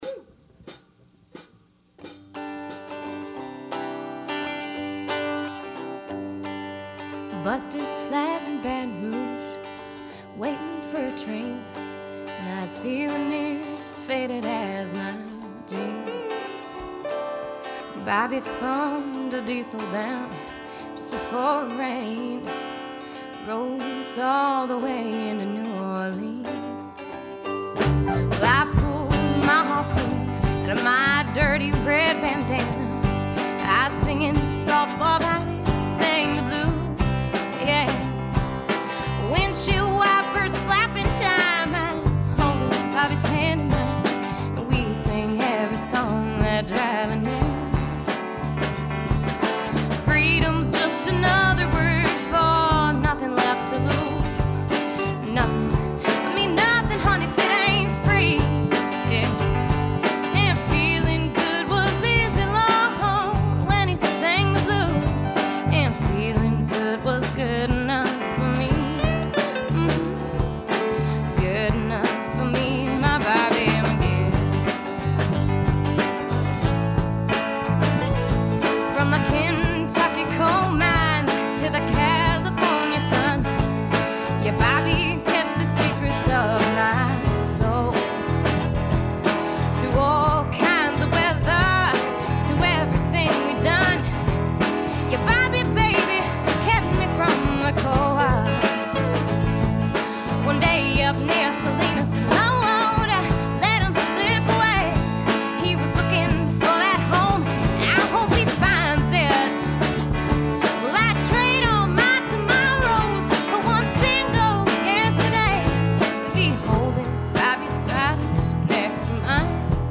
We had the monumental task of mixing 4 tracks to mono to allow three more tracks of overdubs.  I felt that the guitar part, in particular, needed a 'dynamic sensitivity' relative to the vocal, two acoustic guitars - nashville tuned - were OD'd plus a lead electric for 'obbligato.'
We achieved a more balanced mix with just a hint of 'verb and got to play with the graphic EQ on the mix buss.
Me and BobbyMcGee (raw mix)